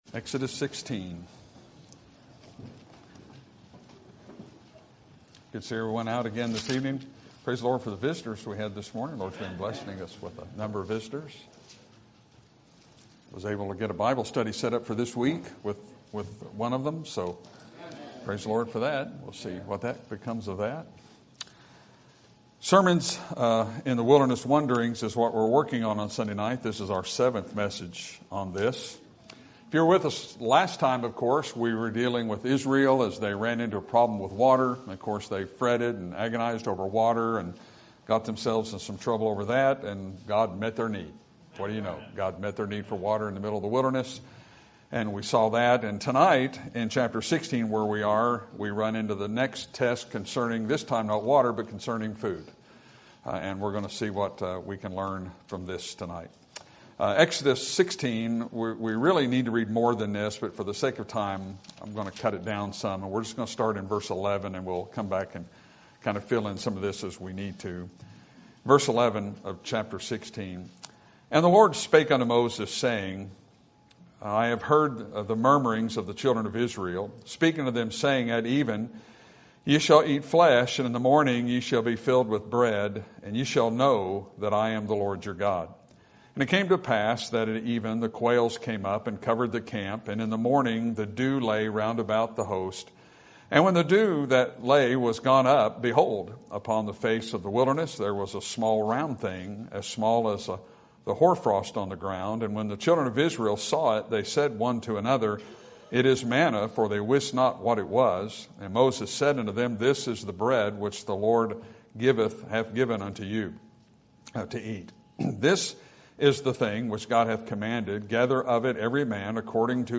Sermon Date: Sunday, September 7, 2025 - 6:00pm Sermon Title: Manna, what is It?